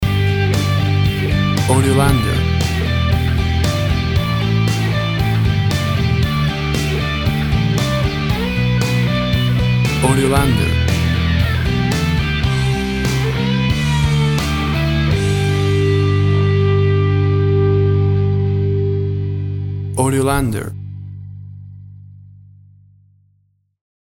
A big and powerful rocking version
Full of happy joyful festive sounds and holiday feeling!.
Tempo (BPM) 100